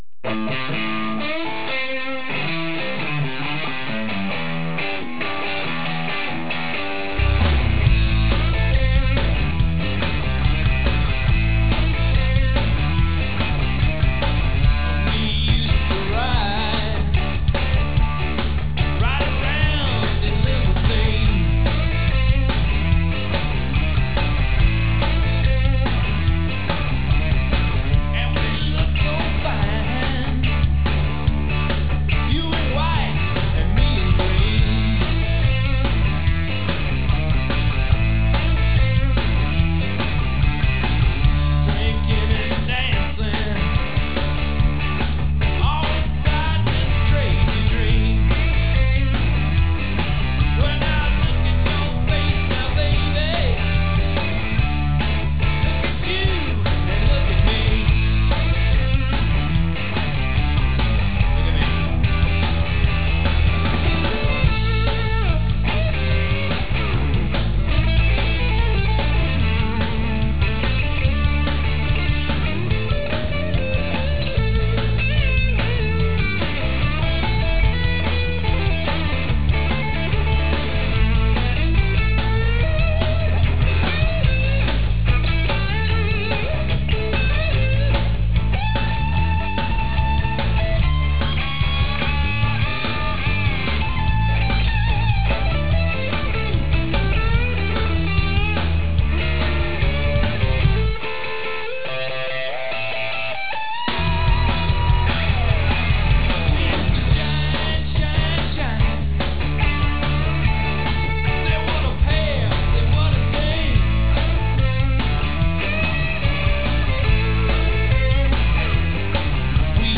These guys rock like a pickup truck going down a dirt road.
Guitar/Vocals
Bass
Drums